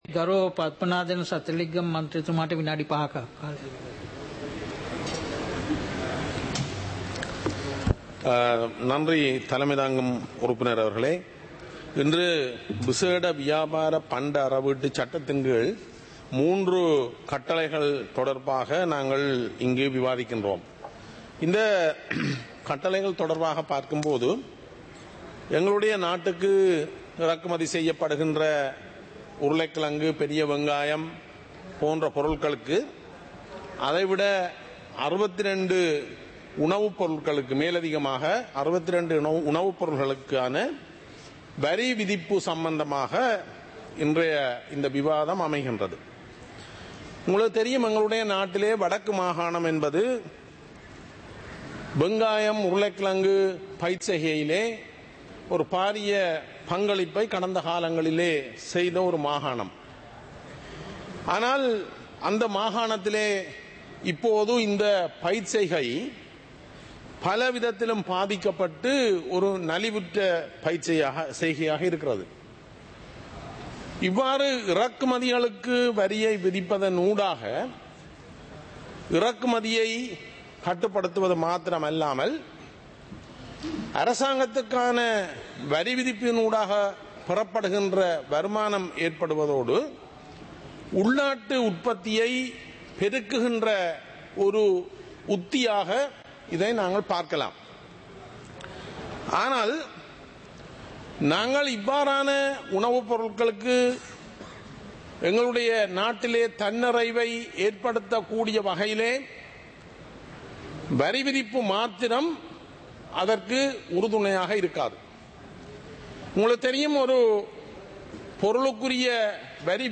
இலங்கை பாராளுமன்றம் - சபை நடவடிக்கைமுறை (2026-02-18)